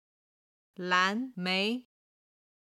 軽声の音は音源の都合上、四声にて編集しています。
蓝莓　(lán méi)　ブルーベリー
31-lan2mei2.mp3